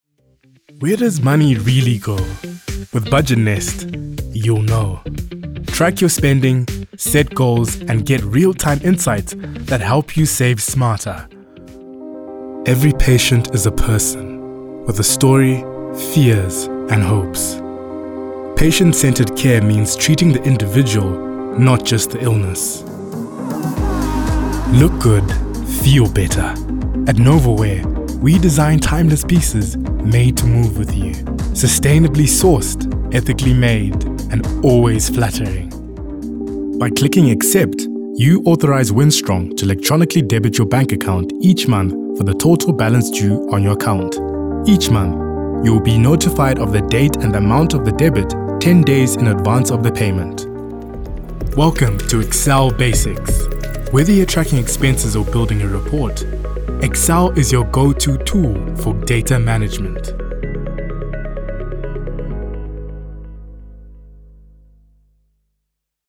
Vídeos corporativos
Micrófono de condensador Rode NT1
Adulto joven